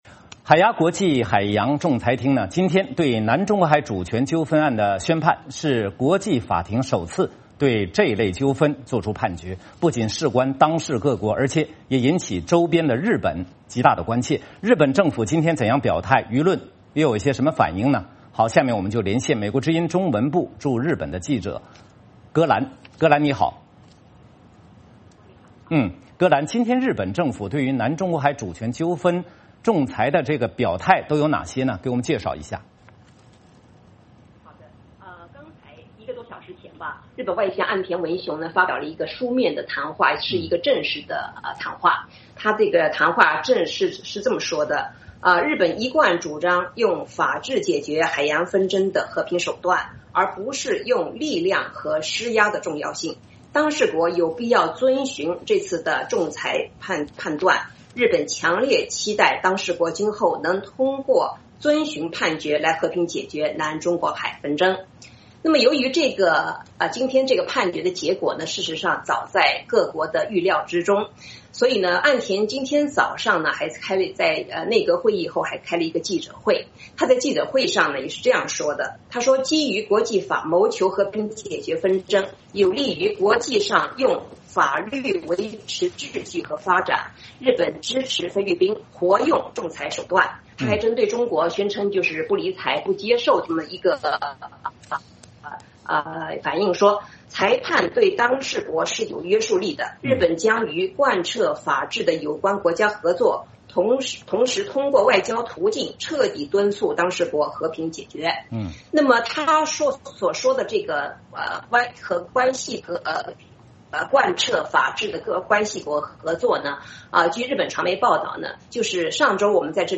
VOA连线: 日本各界对海牙国际法庭裁决的反应